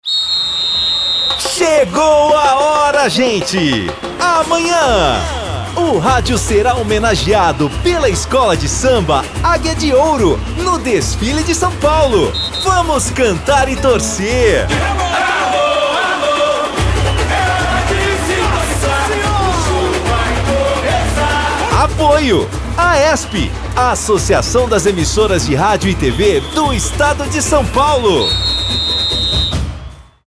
Sob a coordenação da AESP (Associação das Emissoras de Rádio e TV do Estado de São Paulo), foram distribuídos spots de rádio com uma contagem regressiva para o desfile da Águia de Ouro, que ocorrerá neste sábado.